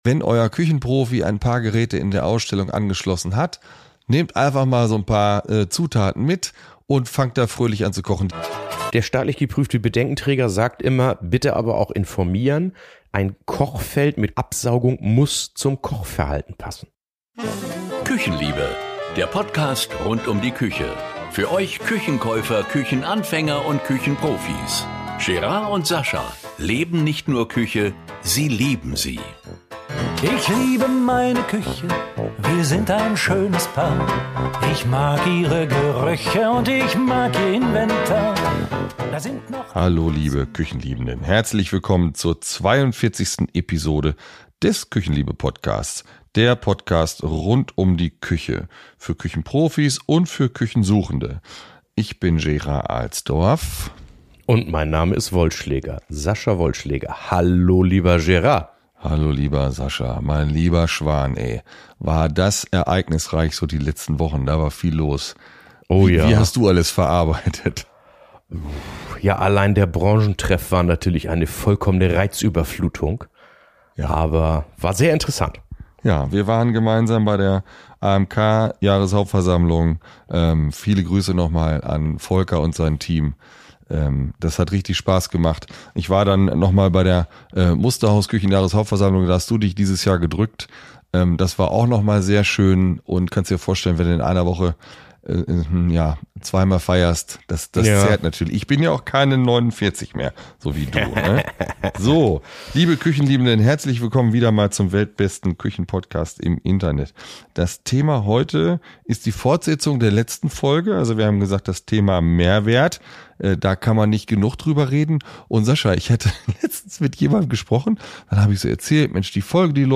Zwei Männer, die Küche nicht nur lieben, sondern leben! Mit Herz, Humor und jeder Menge Erfahrung rollen sie der Einbauküche den roten Teppich aus.